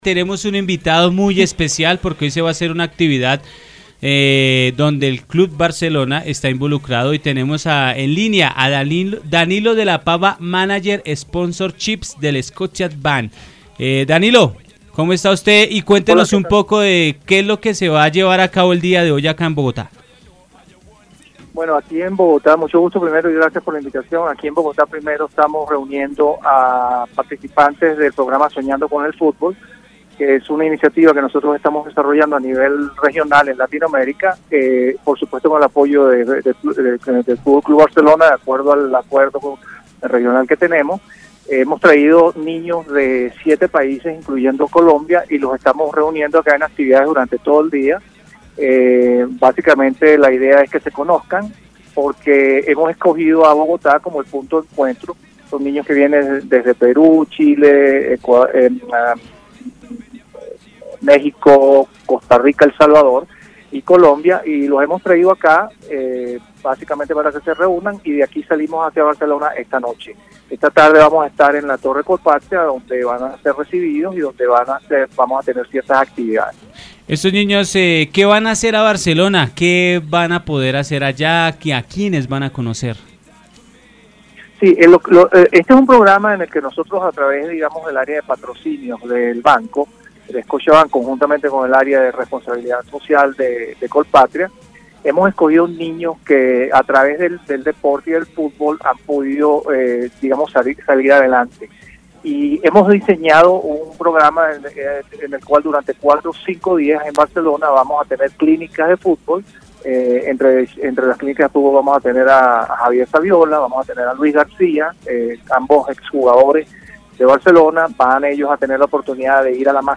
En una entrevista para Entretiempo